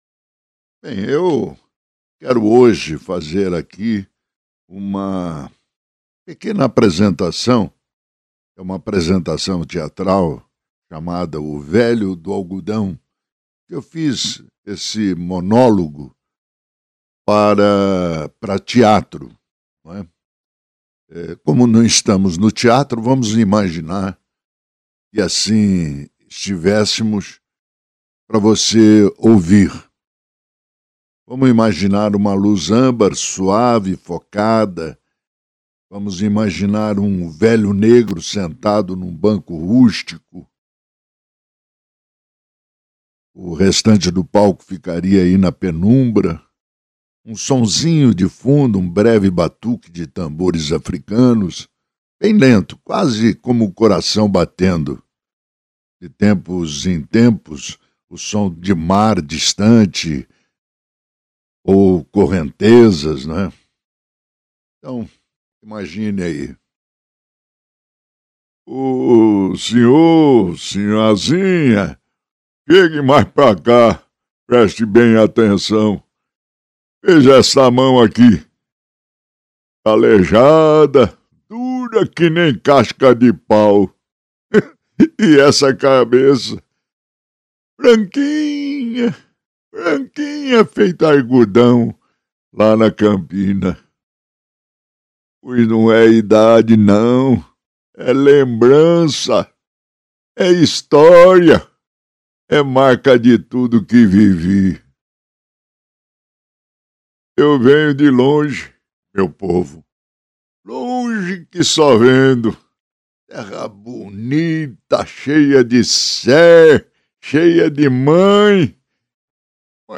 Apresentação Teatral.